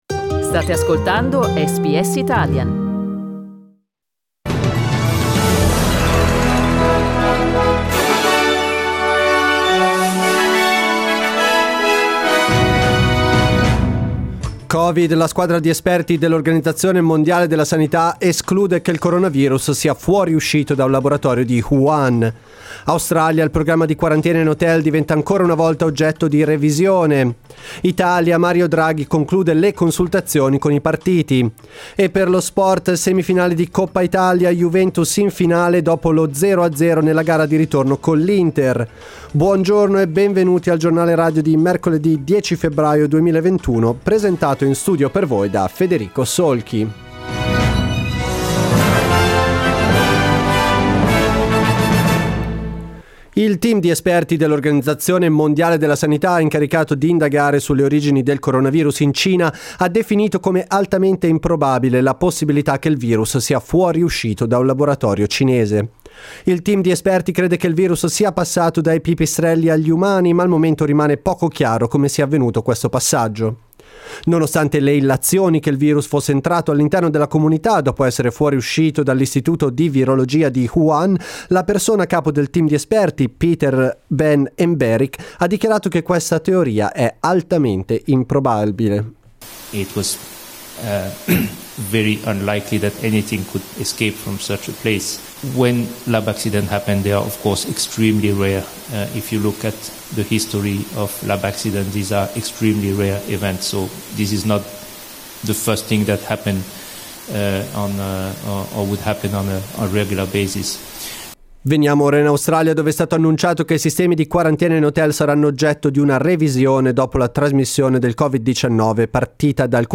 Our news bulletin in Italian.